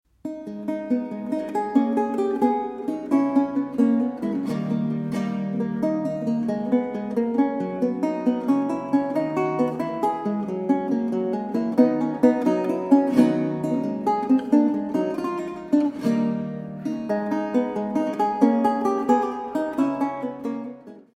Baroque Guitar and Theorbo
Kaple Pozdvižení svatého Kříže, Nižbor 2014